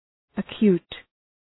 Προφορά
{ə’kju:t}